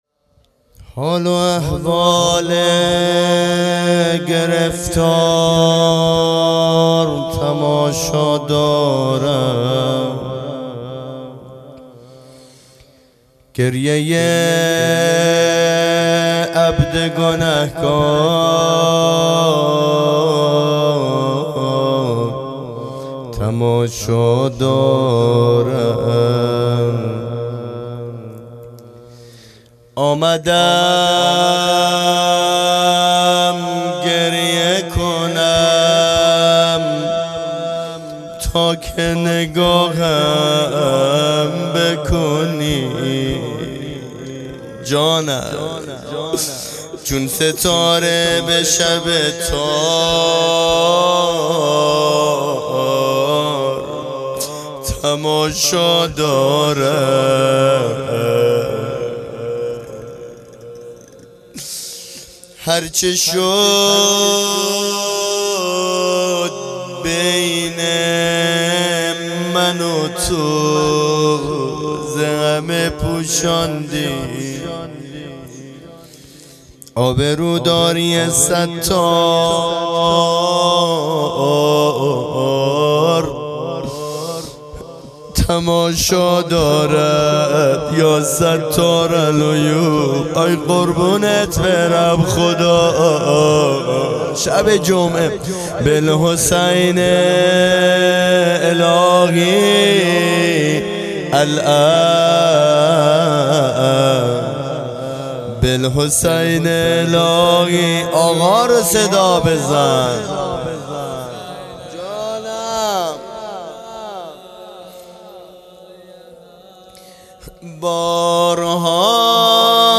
خیمه گاه - هیئت بچه های فاطمه (س) - روضه | حال و احوال گرفتار تماشا دارد | پنجشنبه ۳۰ بهمنماه ۹۹